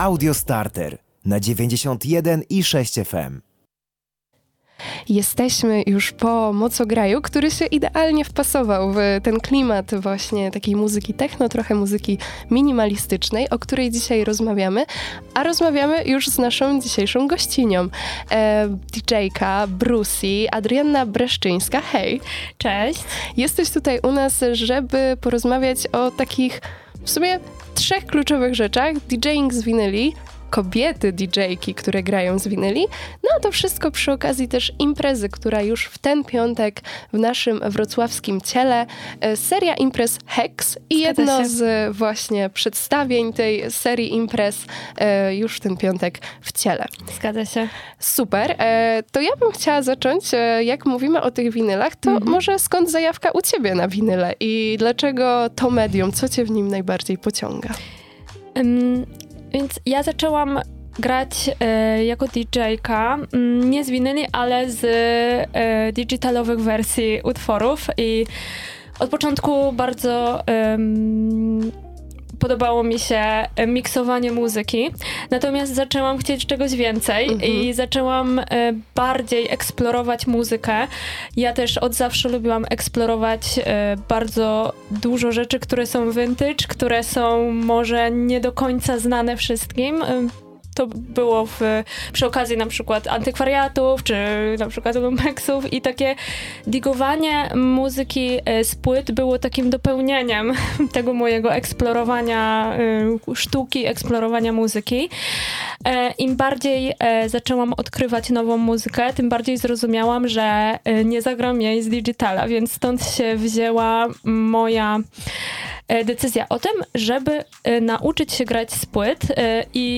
Kobiety za konsolami też oddają hołd klasyce – rozmowa